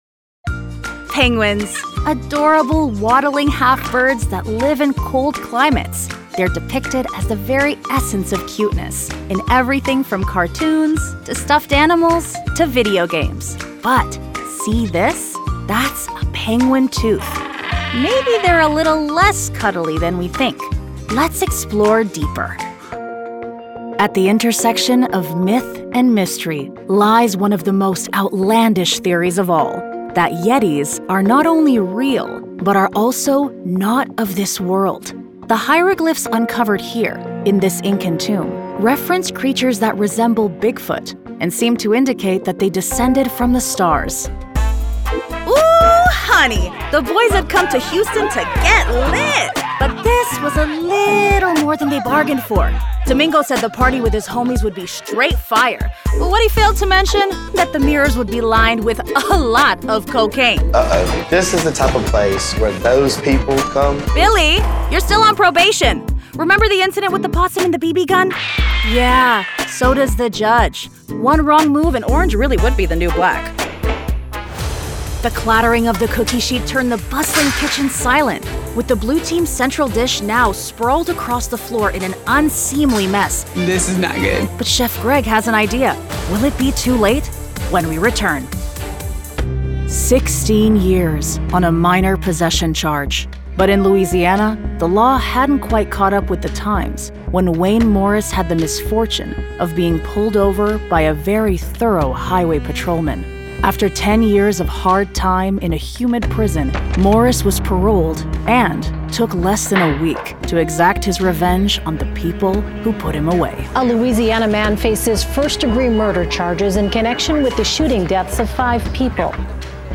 Has Own Studio
NARRATION 😎